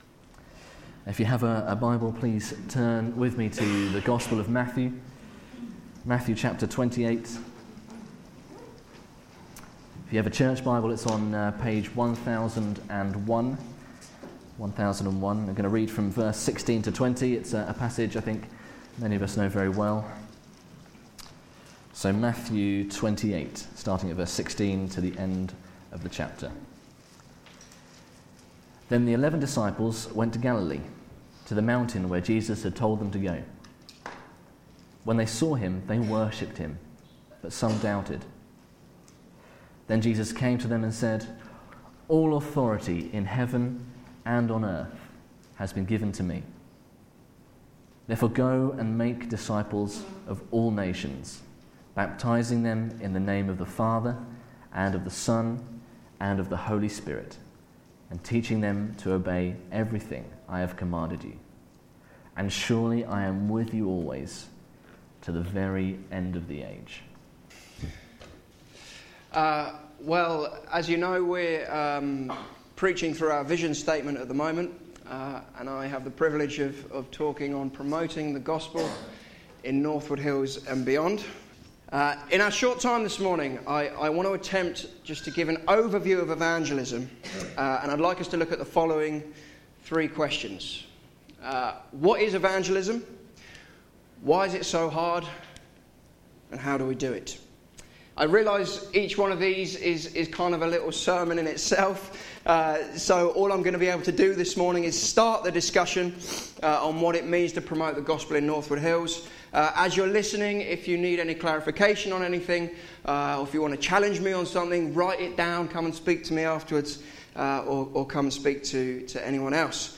Media for Sunday Service
Theme: Promoting the Gospel in Northwood Hills and Beyond Sermon